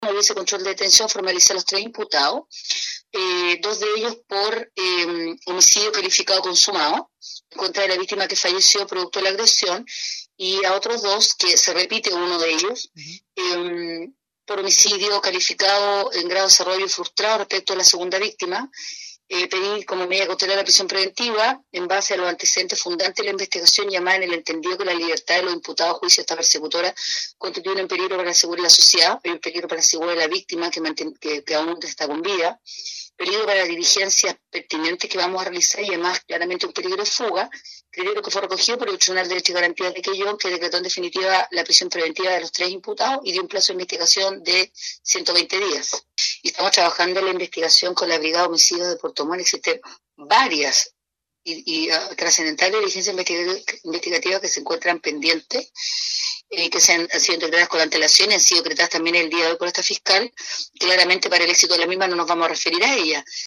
Los imputados fueron puestos a disposición de tribunales, realizándose la audiencia de control de la detención y formalización, quedando, además, en prisión preventiva, expuso la fiscal de esa comuna, Karyn Alegría.